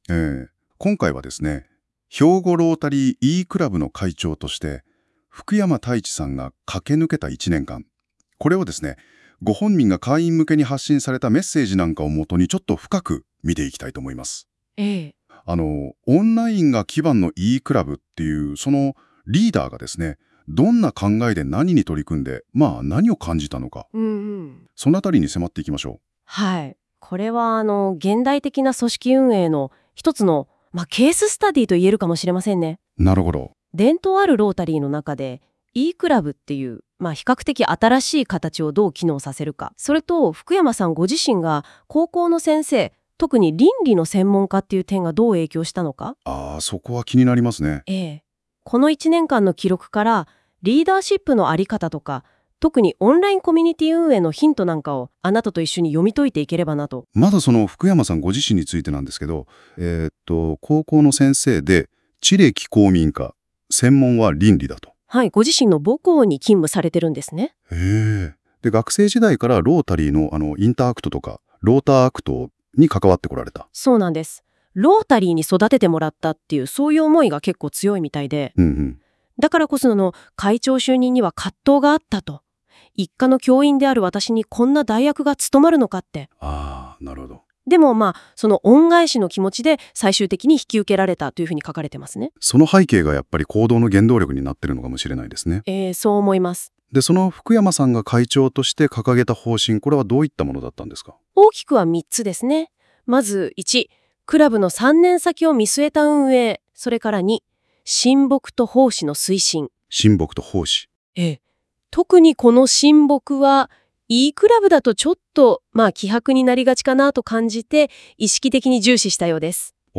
既に会長挨拶にて長々と文章を書き綴っておりますので、年度末のあいさつに代えて、この１年間のハイライトを、「NotebookLM」の生成ＡＩを活用してポッドキャスト風の音声データを作成しました。